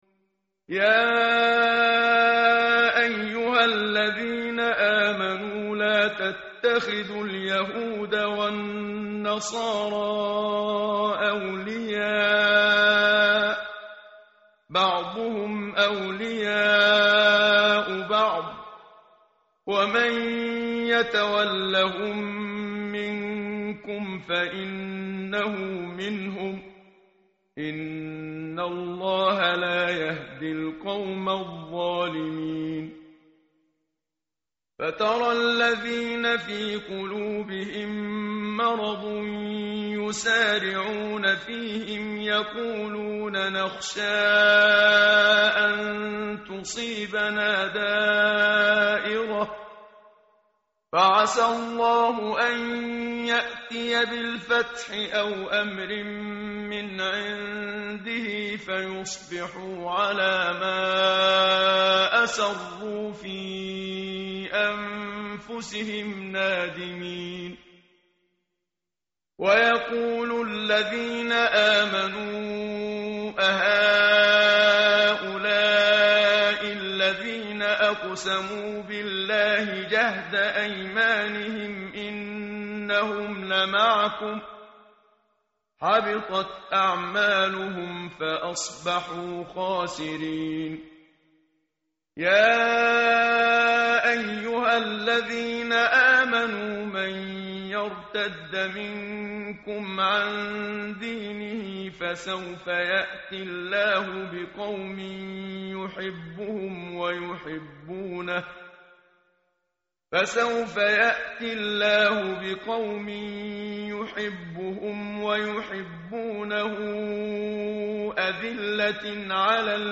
متن قرآن همراه باتلاوت قرآن و ترجمه
tartil_menshavi_page_117.mp3